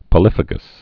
(pə-lĭfə-gəs)